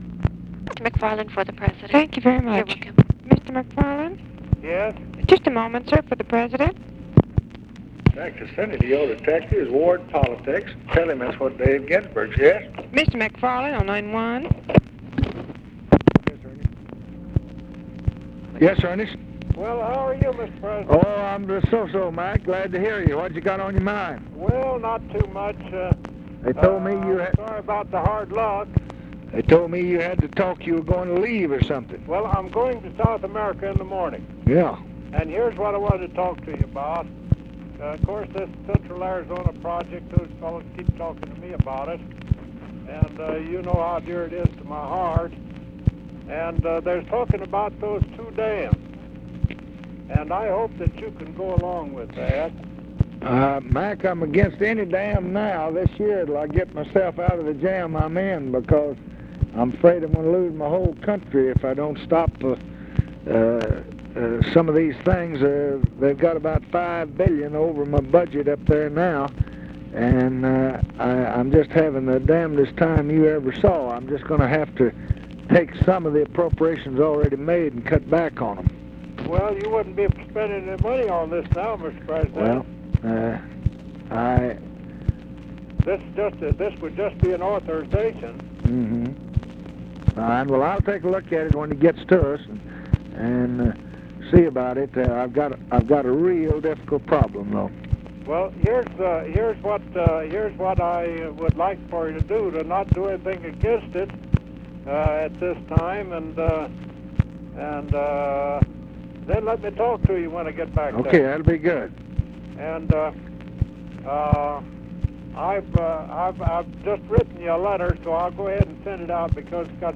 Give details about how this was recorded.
Secret White House Tapes